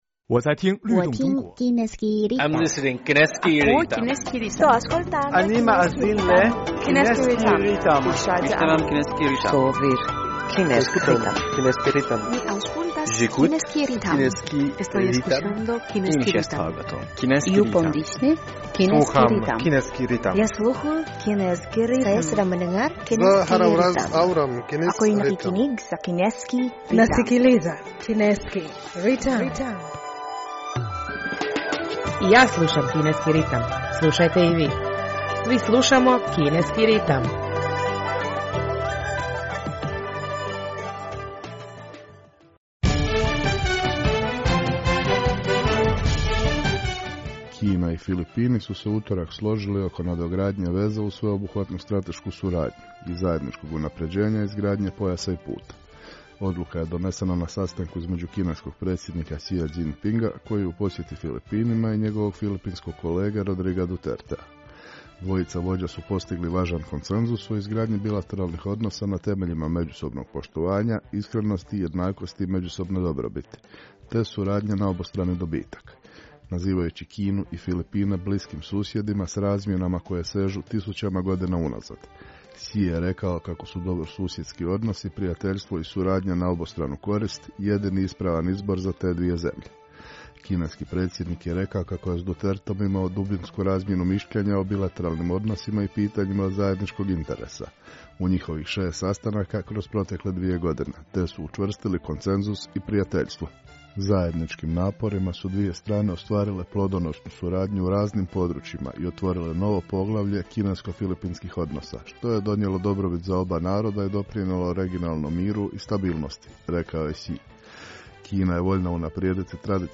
Slušate program na hrvatskom jeziku Kineskog radio Internacionala!